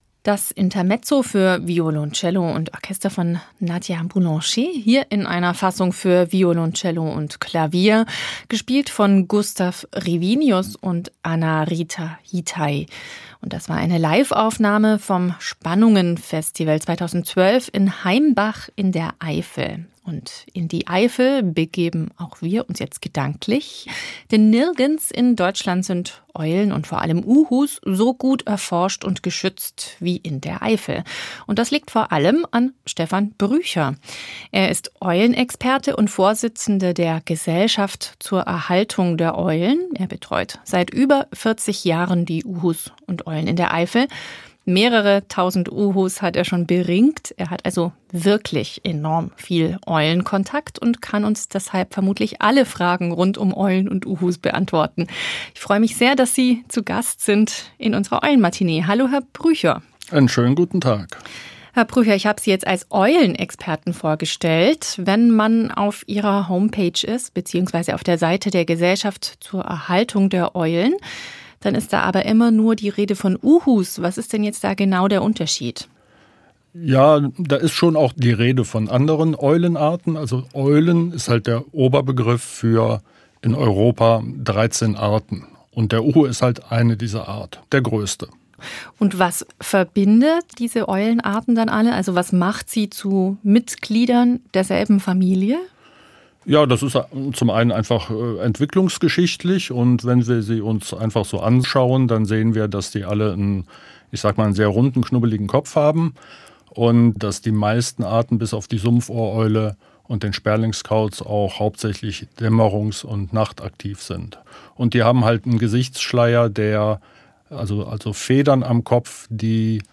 Im Matinee-Gespräch erzählt er, warum Uhus gefährdet waren, wie es gelungen ist, sie wieder anzusiedeln. Er berichtet von Dramen vor der Webcam und vom schwindenden Lebensraum für Käuze.